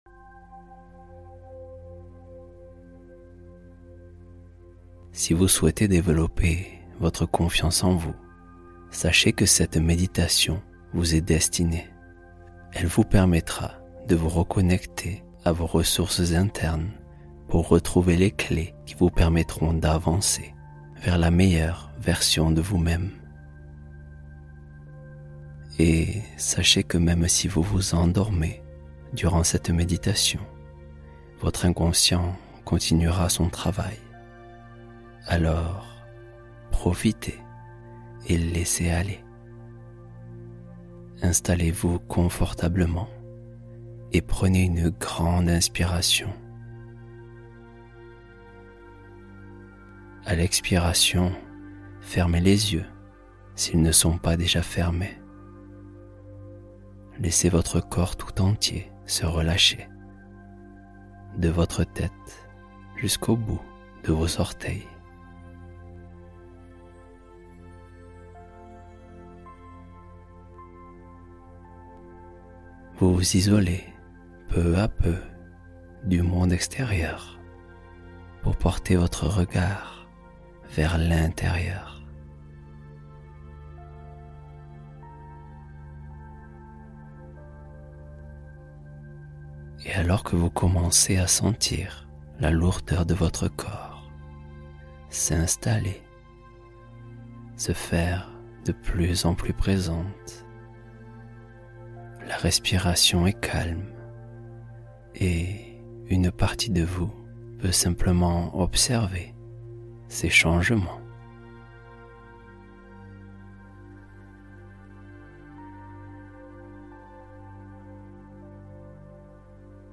Assurance Intérieure : Méditation pour stabiliser la confiance en soi